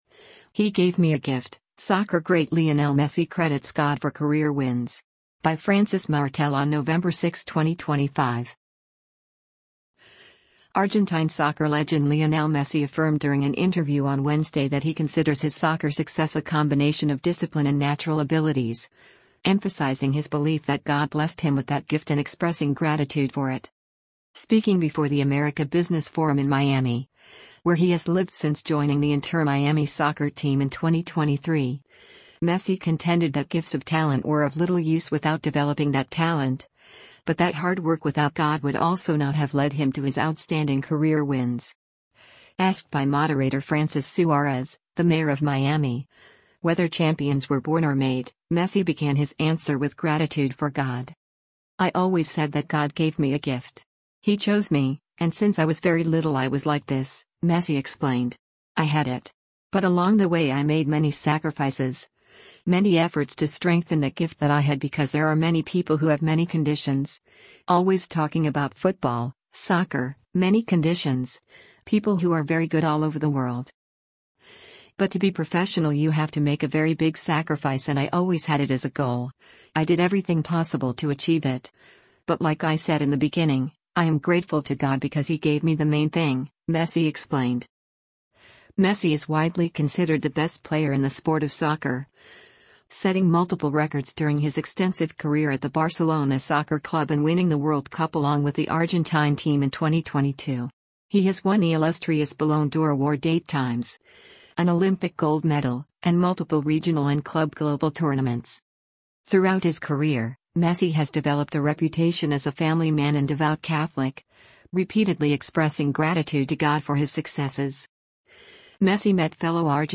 Argentine soccer legend Lionel Messi affirmed during an interview on Wednesday that he considers his soccer success a combination of discipline and natural abilities, emphasizing his belief that God blessed him with that gift and expressing gratitude for it.
Asked by moderator Francis Suárez, the mayor of Miami, whether champions were “born or made,” Messi began his answer with gratitude for God.